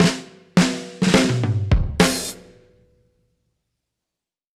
Index of /musicradar/dub-drums-samples/105bpm
Db_DrumsA_Dry_105_04.wav